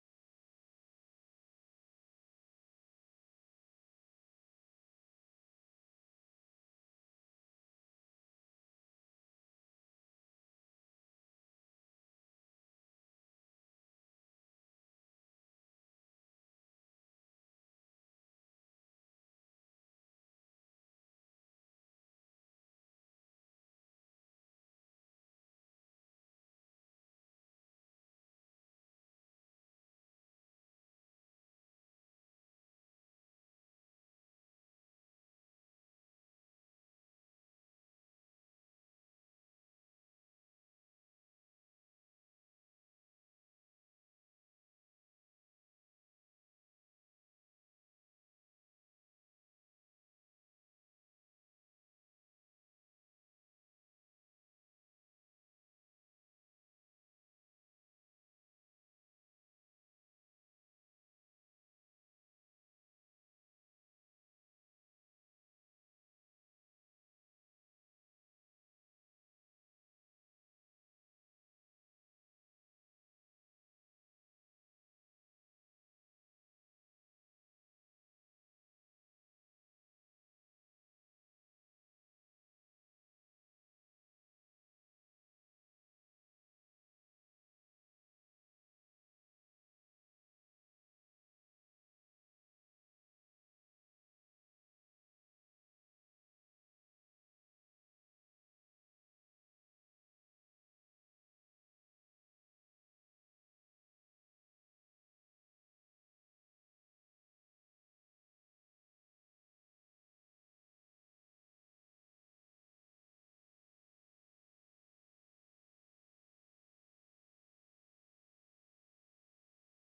Introduction- Round- Waltz- Bossa Nova- Finale
SATB version: